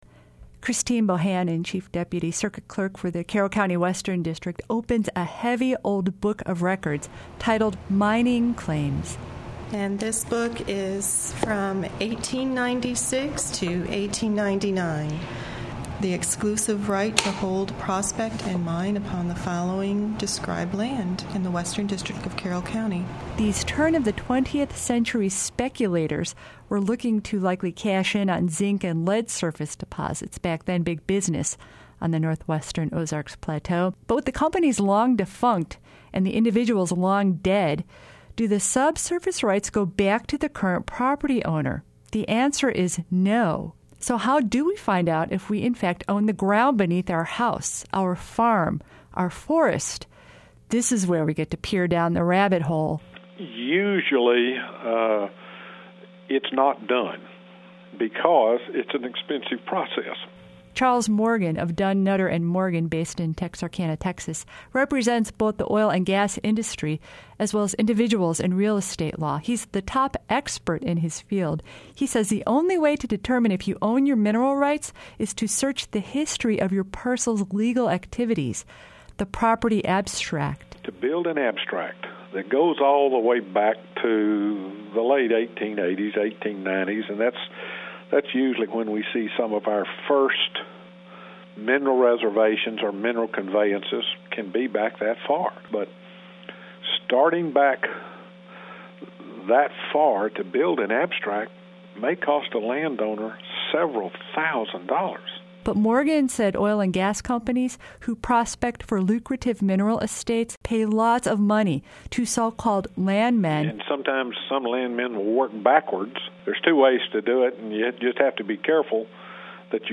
Severed Arkansas Mineral Estates MineralEstates.mp3 The ground beneath your home, farm or forest is known as a mineral estate and in many cases someone else owns it. And those surface landholders on lucrative ground, such as the Fayetteville Shale, may be at risk. We hear from a mineral estates expert, affected landholder and state political activist seeking to pass a landholders bill of rights.